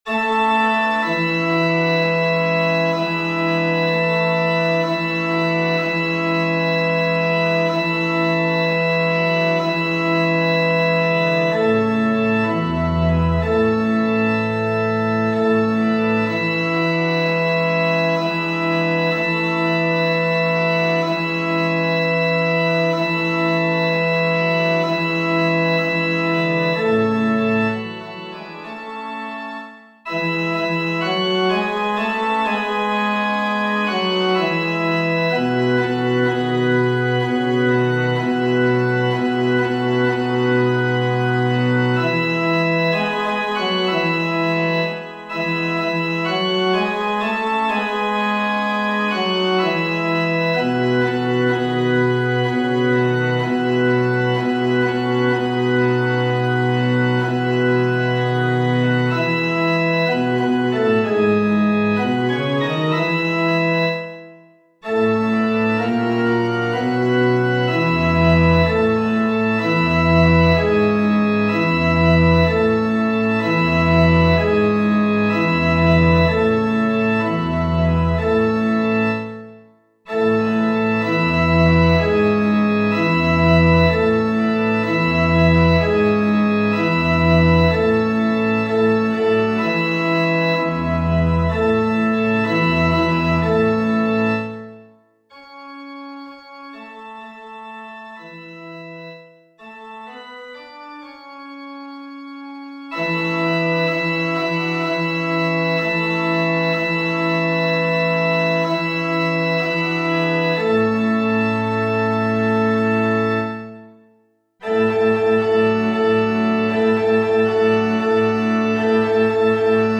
Rolnicka_bas.mp3